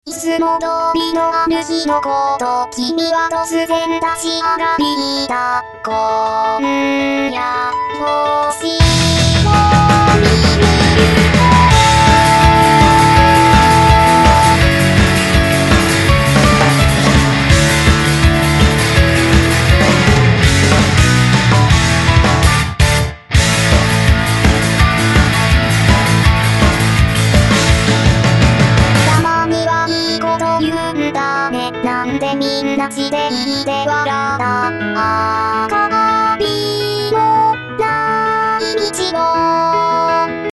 消えたせいでまた音痴に・・・\(^o^)/
いざ曲に合わせるとちょいずれてる悲劇www